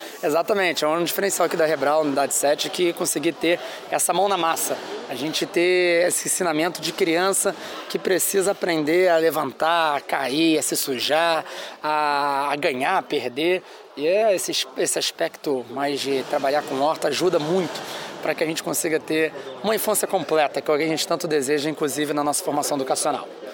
O secretário falou da importância das creches.
Renan Ferreirinha também falou sobre a horta que existe na creche que vai ser utilizada para a alimentação das crianças.